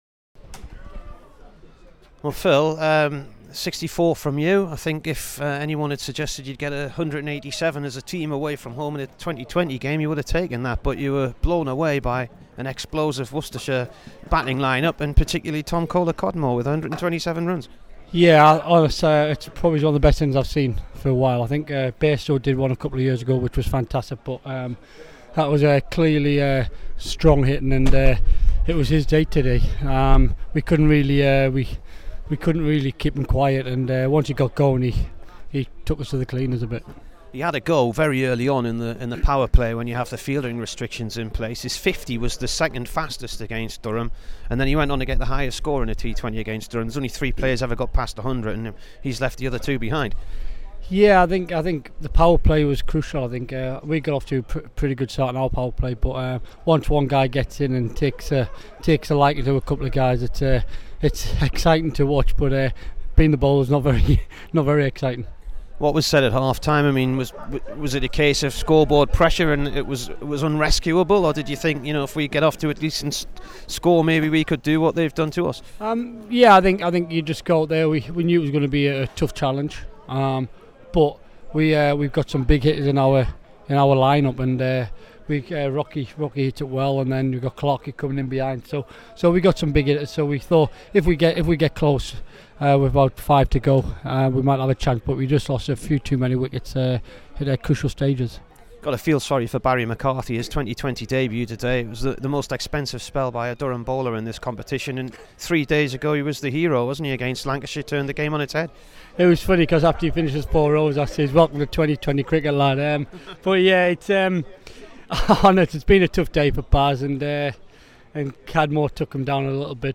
Here is the Durham opener following his 64 in the T20 defeat at Worcester.